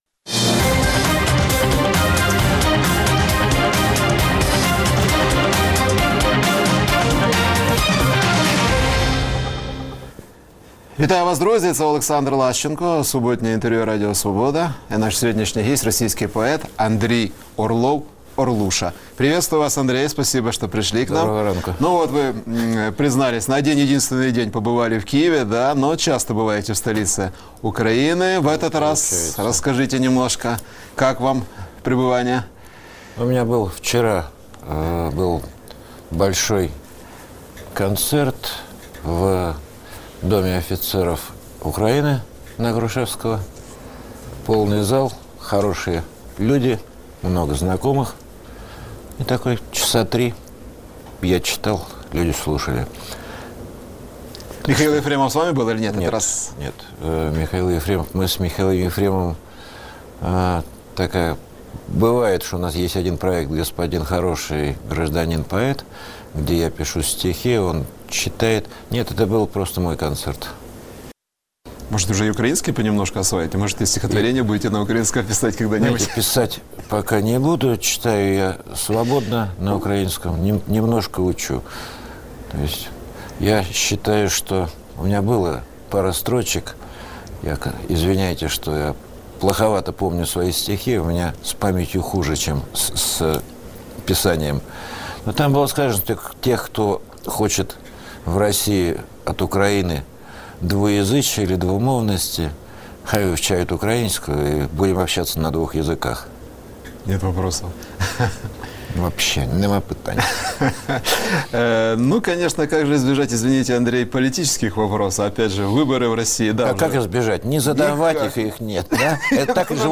Суботнє інтерв’ю